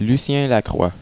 (french pronunciation)